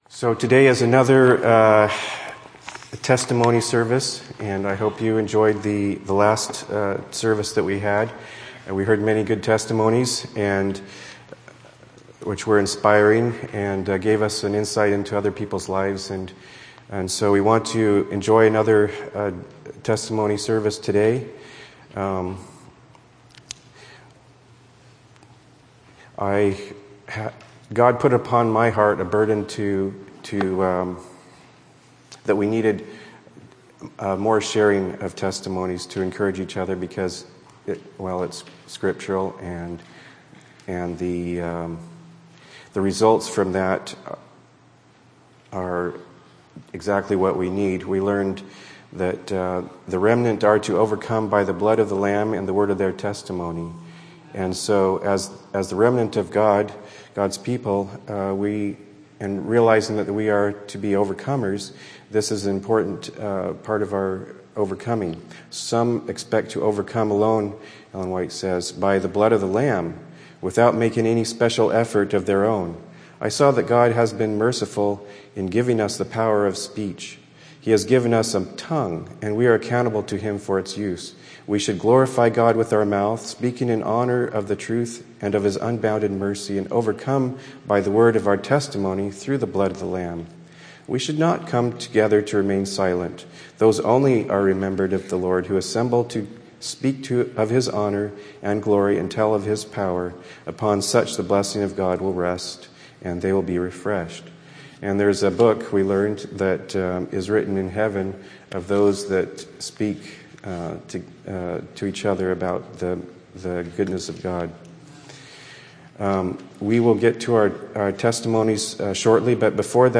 Sabbath Fellowship Group Testimony